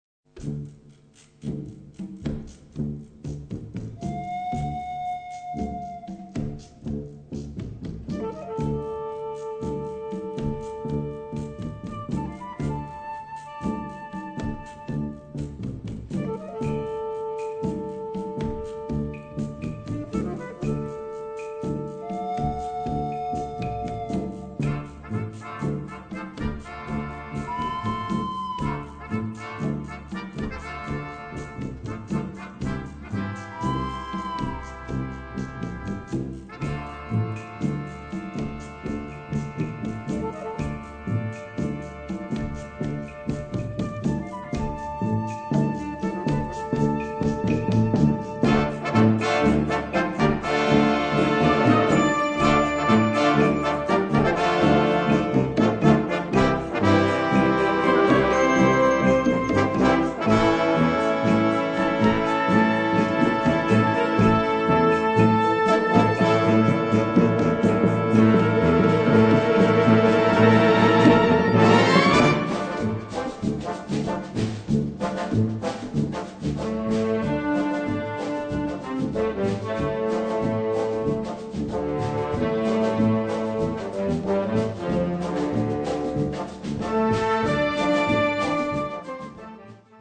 Categorie Harmonie/Fanfare/Brass-orkest
Subcategorie Muziek uit Zuidamerika (in stijl)
Bezetting Ha (harmonieorkest)